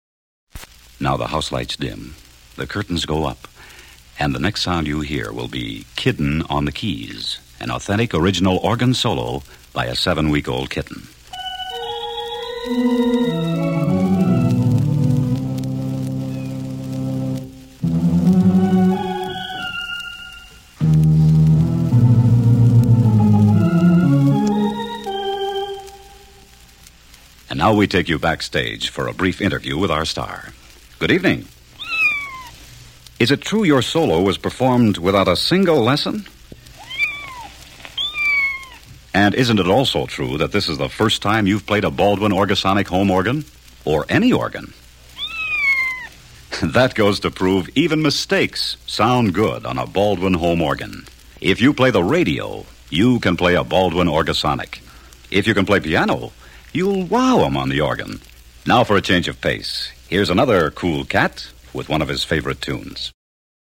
kitten.mp3